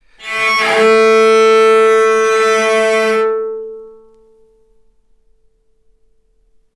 healing-soundscapes/Sound Banks/HSS_OP_Pack/Strings/cello/sul-ponticello/vc_sp-A3-ff.AIF at b3491bb4d8ce6d21e289ff40adc3c6f654cc89a0 - healing-soundscapes - Ligeti Zentrum Gitea
vc_sp-A3-ff.AIF